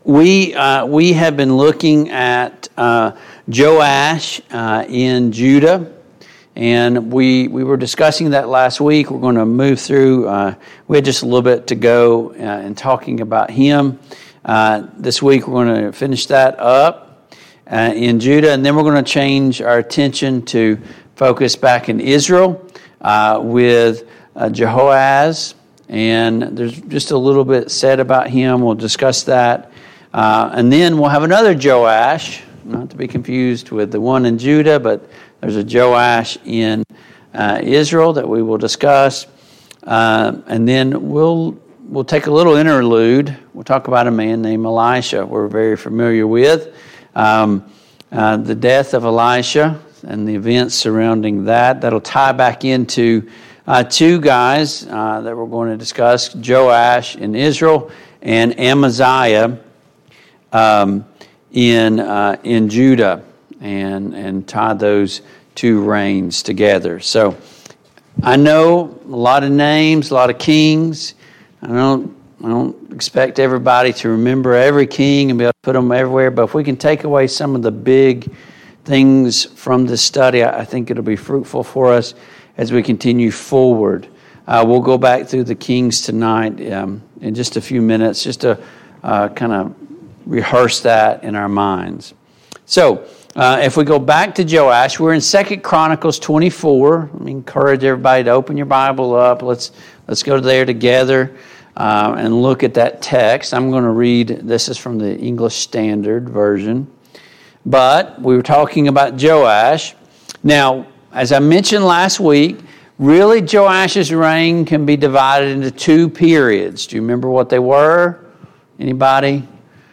The Kings of Israel and Judah Passage: 2 Chronicles 24, 2 Kings 13 Service Type: Mid-Week Bible Study Download Files Notes « 1.